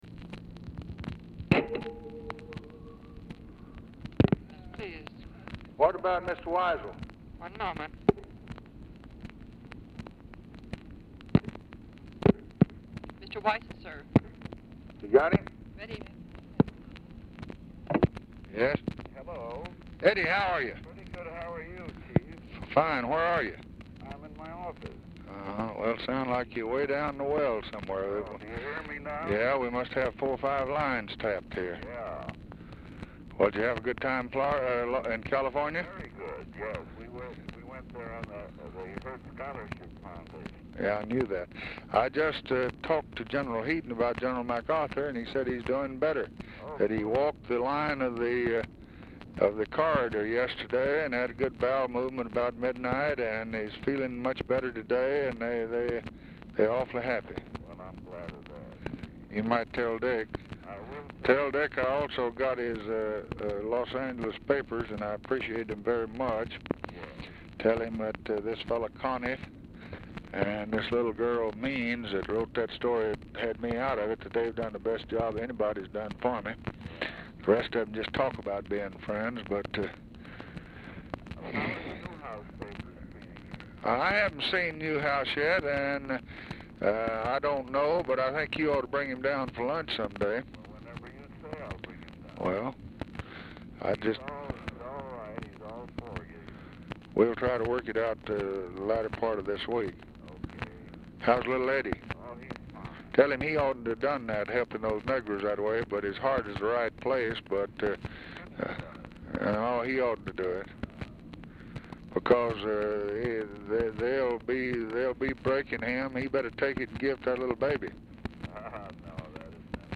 POOR SOUND QUALITY; WEISL ALMOST INAUDIBLE
Format Dictation belt
Specific Item Type Telephone conversation